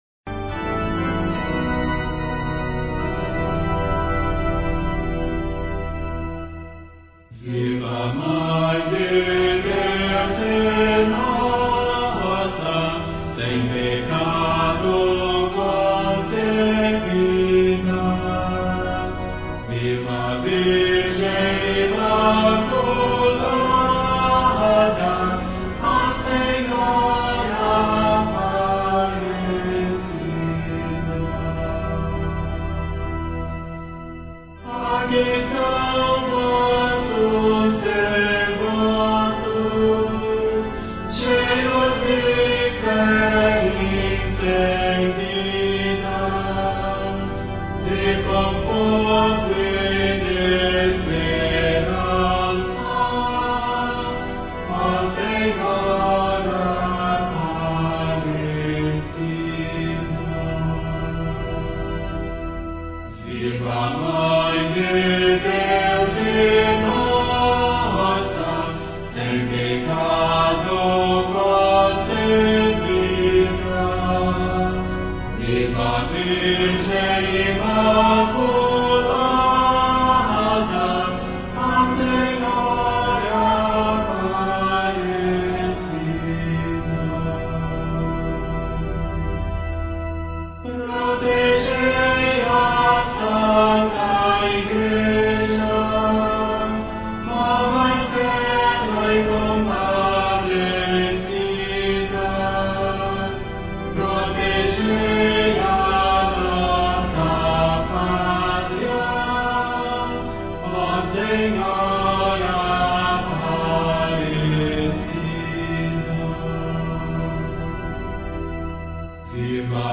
HINO VIVA A MÃE DE DEUS E NOSSA